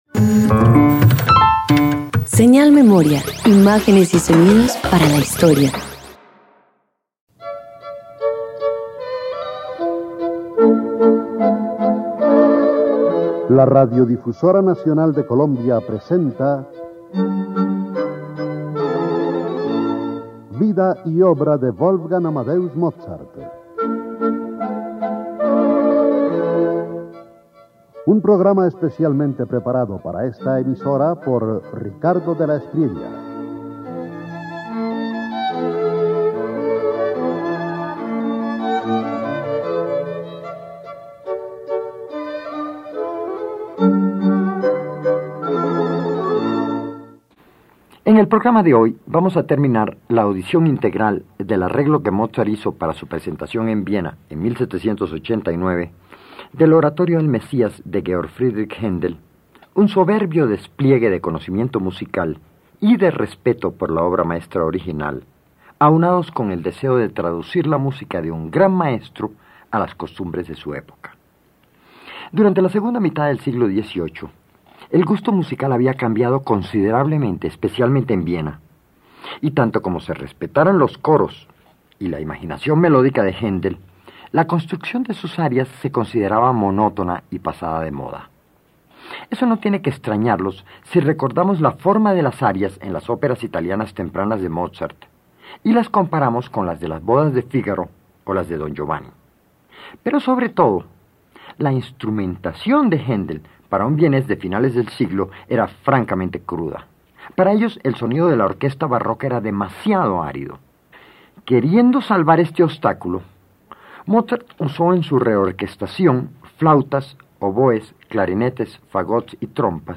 298 Oratorio El Mesías de Handel reorquestado por Mozart Parte IV_1.mp3